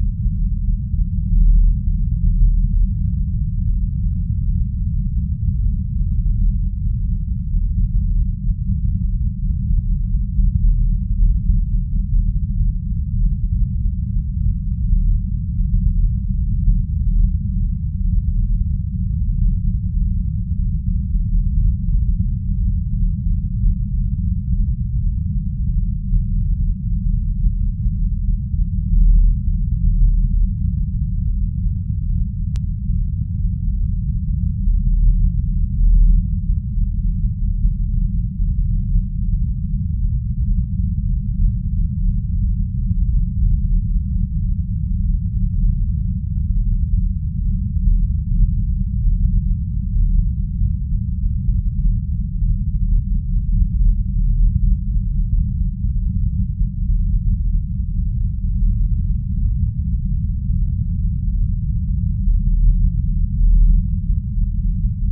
warpengine.wav